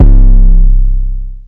808 13 [ drippy ].wav